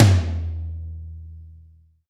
Index of /90_sSampleCDs/Roland - Rhythm Section/KIT_Drum Kits 6/KIT_LA Kit 3
TOM AC.TOM0O.wav